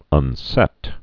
(ŭn-sĕt)